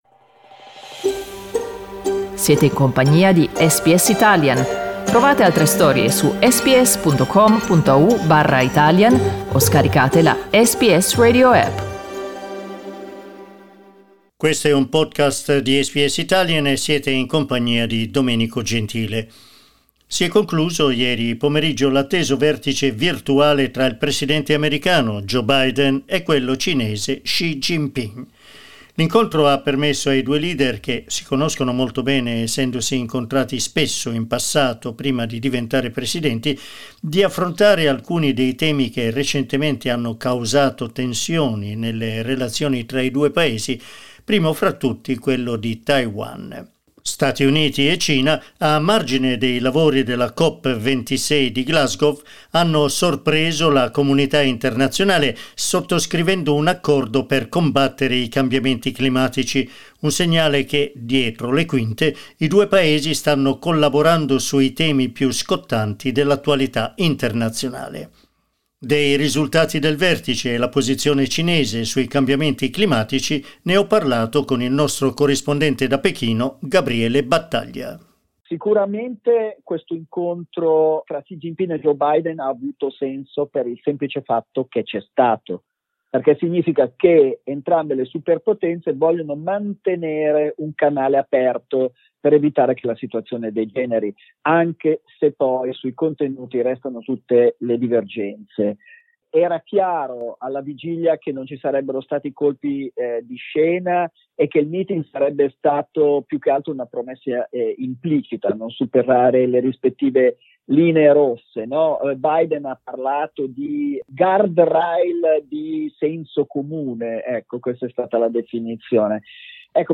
Ascolta il servizio: LISTEN TO Biden e Xi allentano la tensione su Taiwan SBS Italian 11:09 Italian Le persone in Australia devono stare ad almeno 1,5 metri di distanza dagli altri.